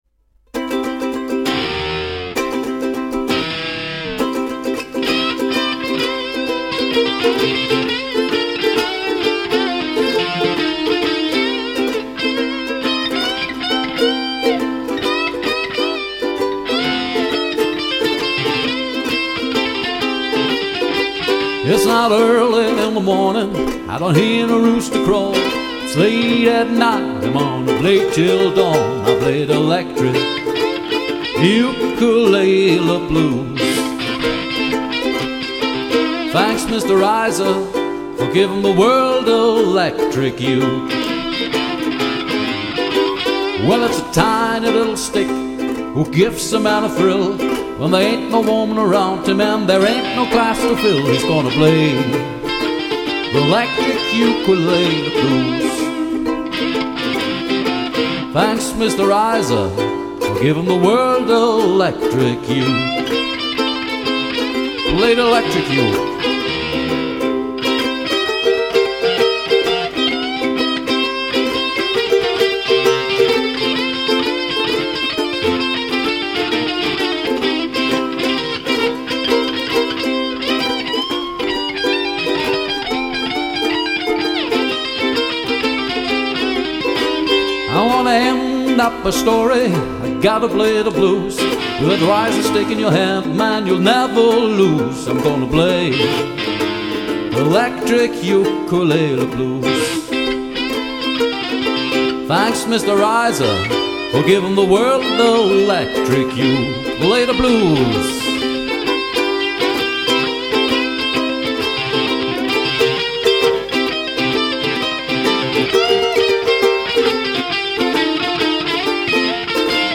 Jetzt hab ich ein Lied gehört, dass auf einer solchen Ukulele gespielt wurde, aber trotzdem
Den Sound aus deinem Beispiel kannst du nur über ein Effekt-Gerät erreichen.
Electric Uke.mp3